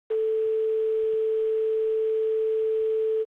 [tytytytytytytytytyty] (son. TEL.)
La tonalité d’invitation à numéroter c’est le petit son qui t’avertit que tu es bien connecté sur le grand réseau et que le monde est à toi pour peu que tu possèdes le juste numéro de téléphone (si tu as encore le mien tu peux m’appeler, je n’attends que ça). La fréquence adoptée par la France, 440 Hz, a la particularité de correspondre au la3 ou A440 produit par les diapasons modernes.